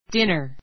dínə r ディ ナ